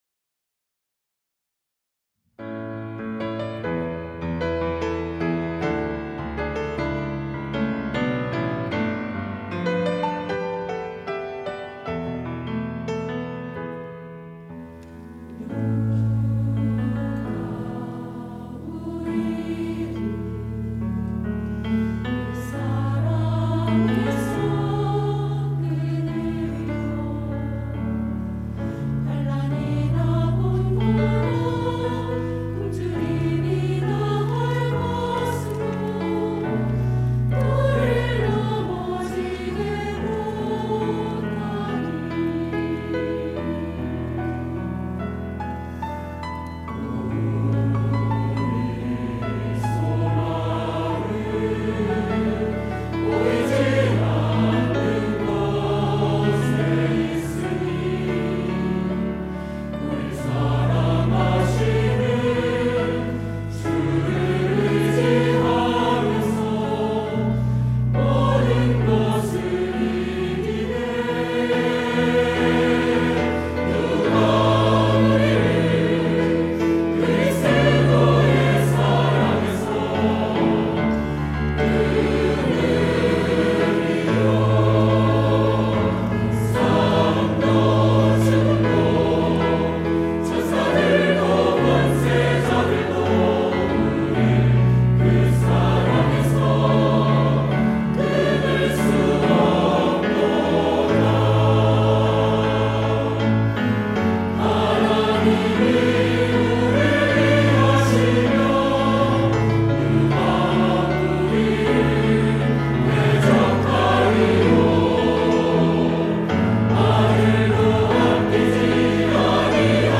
할렐루야(주일2부) - 누가 우리를
찬양대